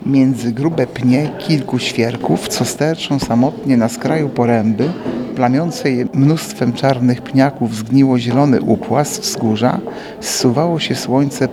Ze „Zmierzchem” Stefana Żeromskiego zmierzył się natomiast Artur Urbański, zastępca prezydenta Ełku, który także nam zaprezentował fragment.